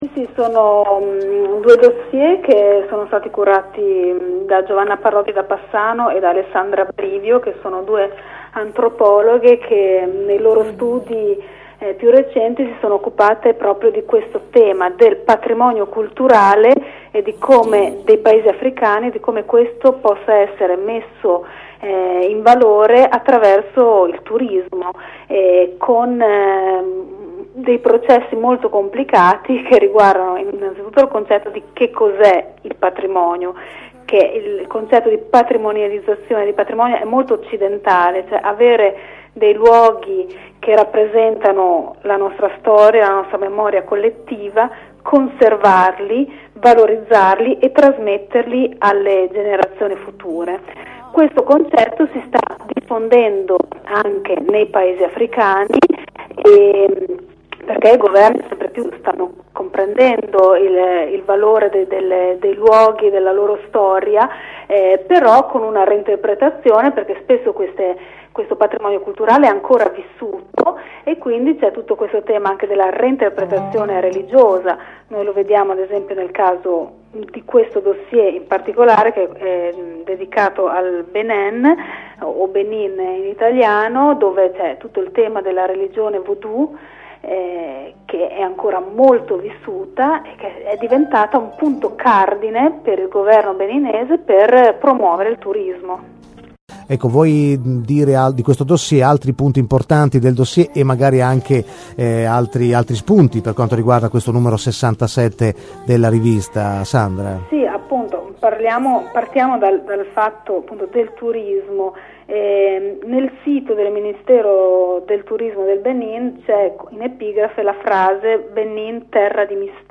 Ieri siamo stati intervistati da Radio città del capo in occasione dell’uscita del nuovo numero di Africa e Mediterraneo.